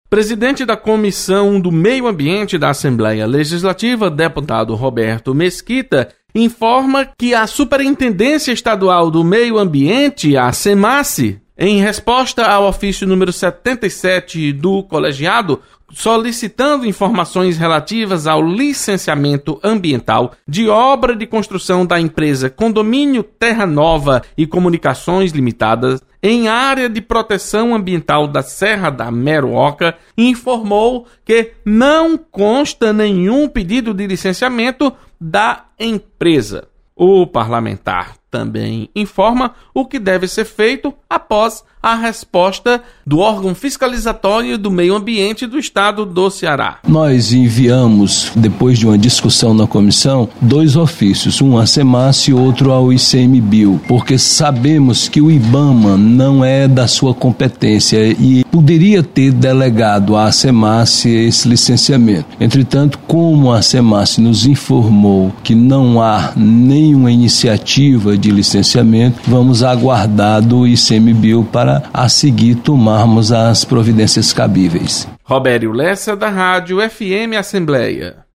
Comissão do Meio Ambiente e Desenvolvimento do Semi-Árido solicita mais esclarecimentos sobre empreendimento na Serra da Meruóca. Repórter